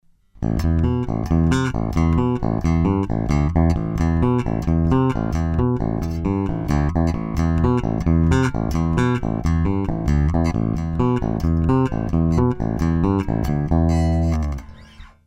Hohneractif.mp3